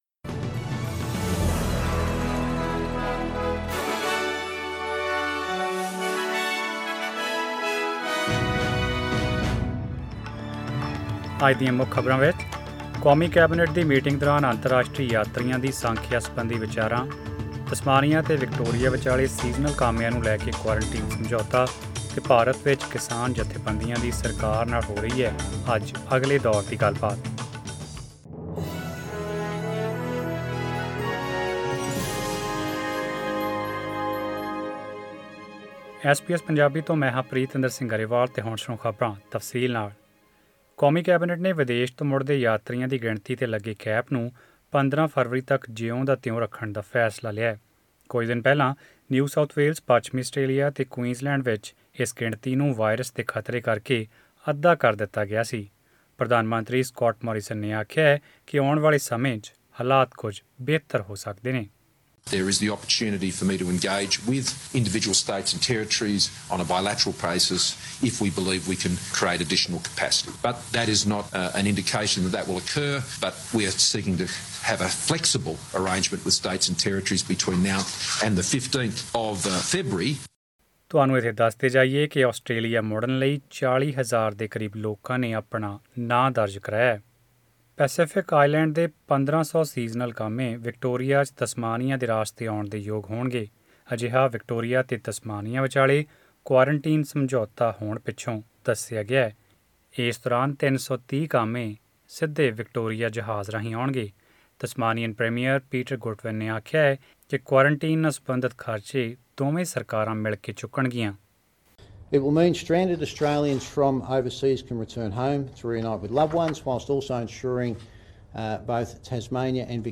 In this bulletin....